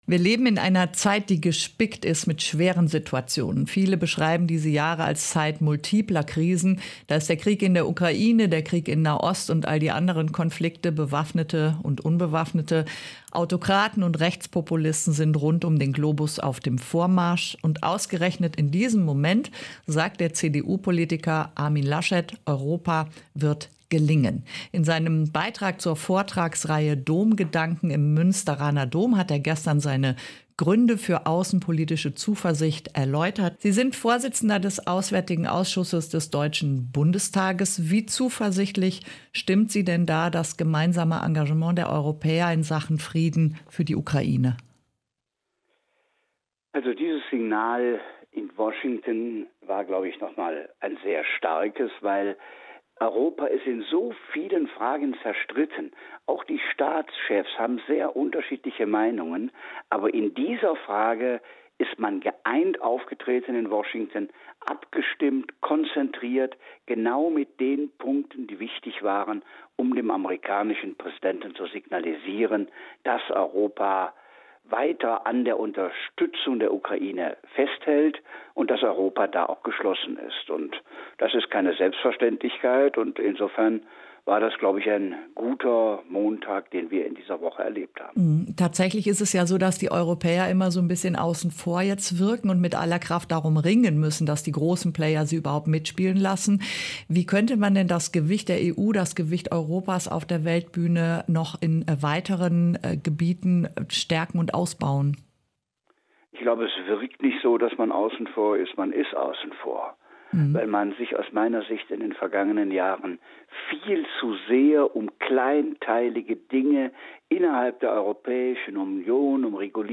Ein Interview mit Armin Laschet (Vorsitzender des Auswärtigen Ausschusses des Deutschen Bundestages)